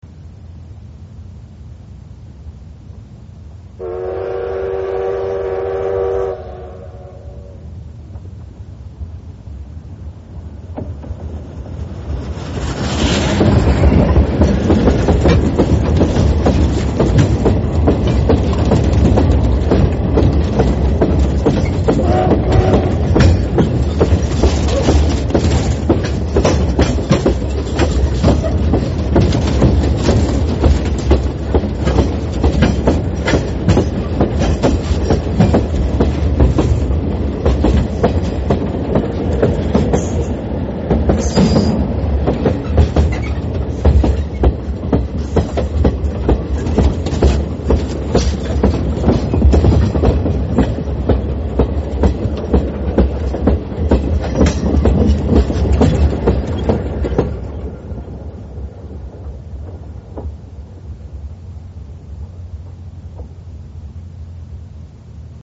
しかし、煙を吐くのは発車時と近鉄をオーバークロスする時だけで、あとは引っ張られて走っている感じでした。
城東貨物線では列車の接近に気付かずに線路を歩く人や犬が多いので、汽笛はよく鳴らしてくれました。
♪　ここの音（この列車とは違います）